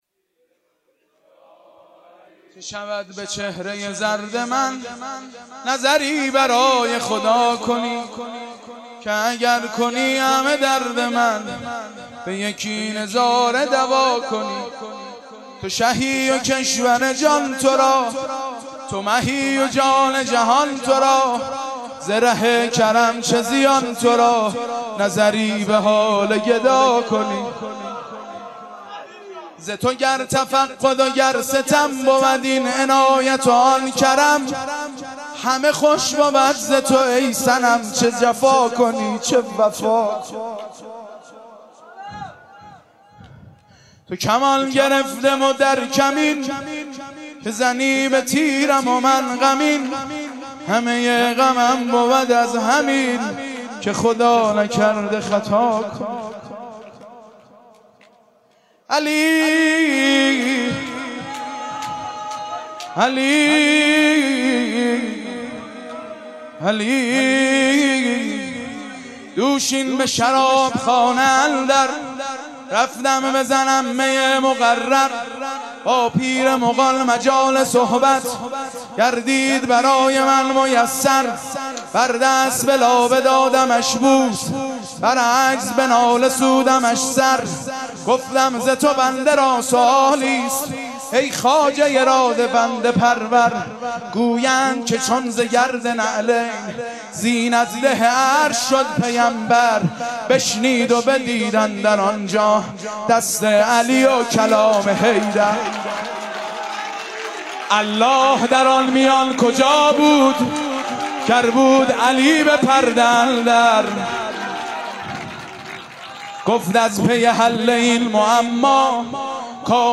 با مدیحه سرایی